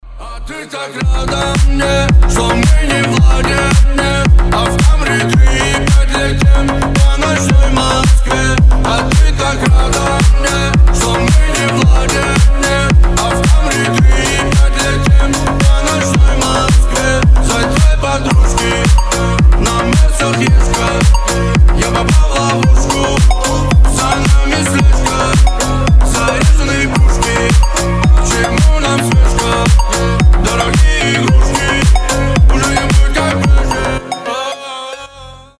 • Качество: 320, Stereo
басы